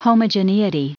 Prononciation du mot homogeneity en anglais (fichier audio)